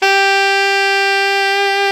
SAX ALTOFF0C.wav